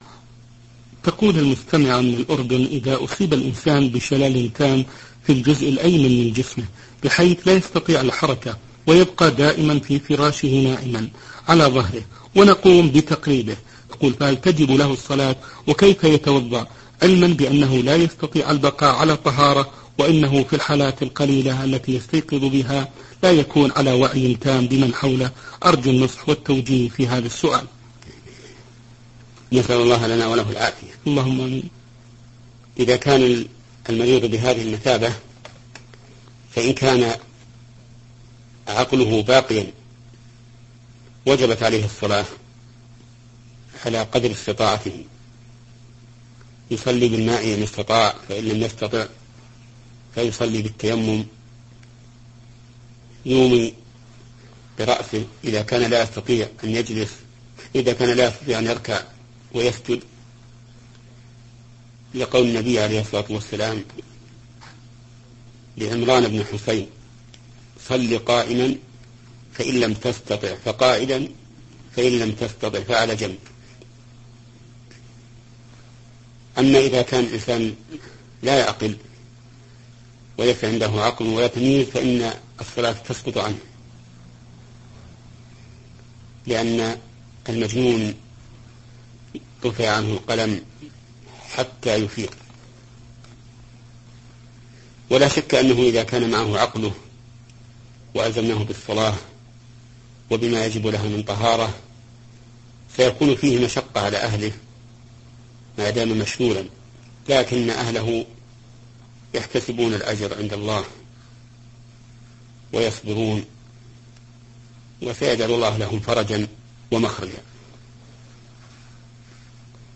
📌الشيخ ابن عثيمين رحمه الله.